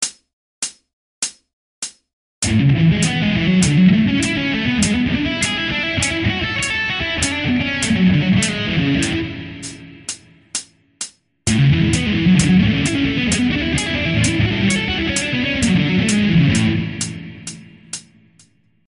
<小スウィープ2>
俺はスウィープに関してはレガート派なので、H&Pをまぜつつ弾いてます。
最初がテンポ100、その後がテンポ130で弾いてます。
何かもつれてる感タップリですが、そこはご愛嬌ということで(笑
Gtr I